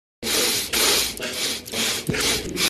Donald Trump - Sniffles Sound
meme